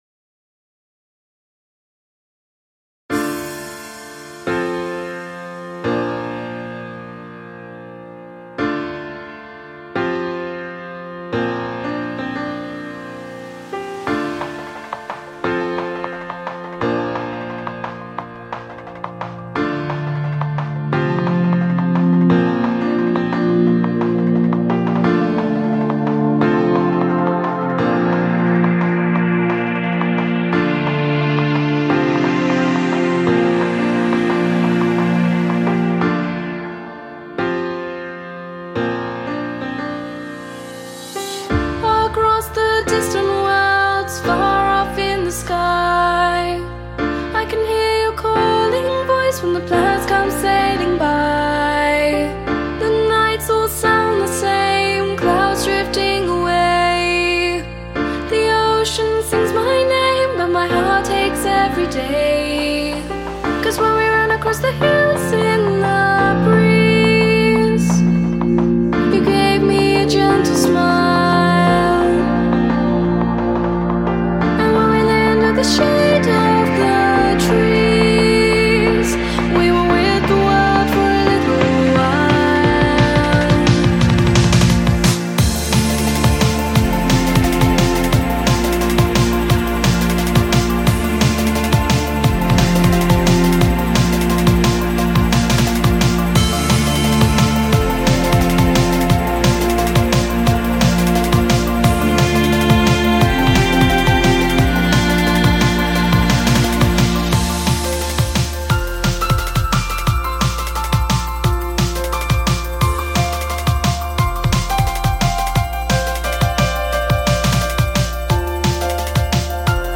Drum and Bass piece